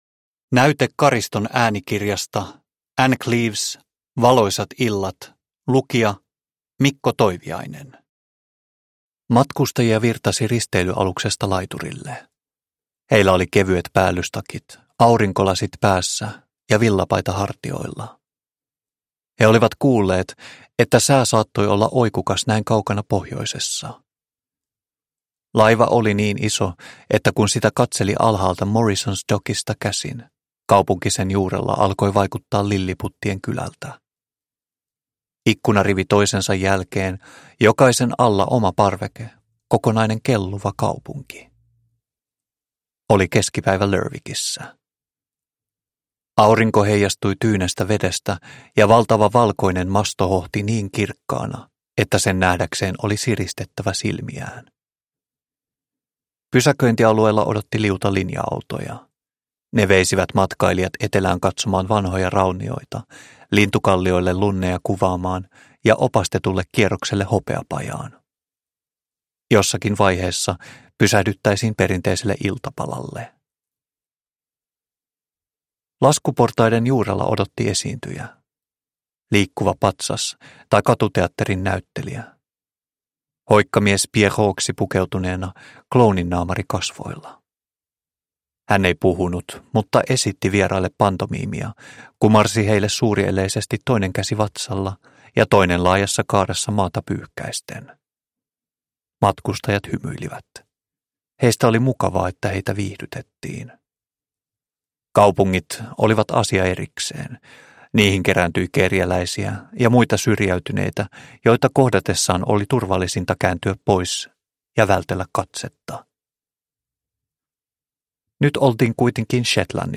Valoisat illat – Ljudbok – Laddas ner